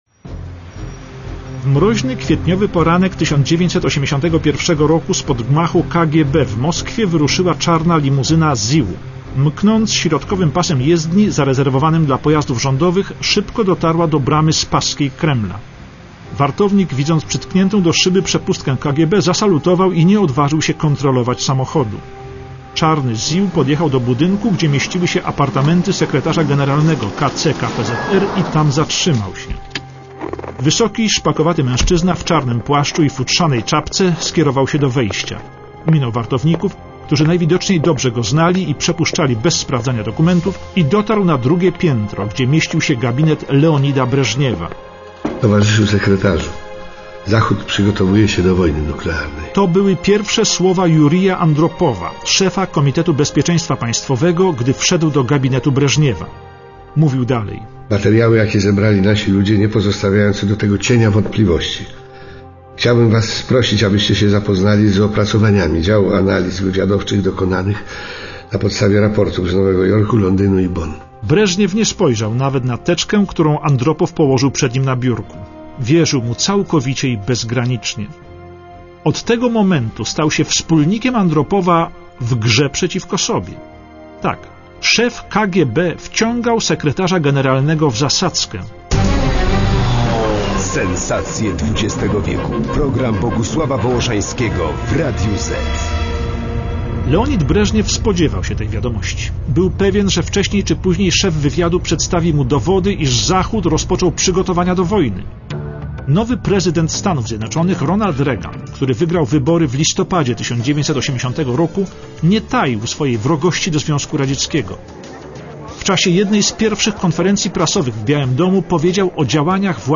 Aktorzy:
Jurij Andropow - Krzysztof Kowalewski
Prezydent Ronald Reagan - Marek Perepeczko